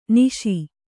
♪ niśi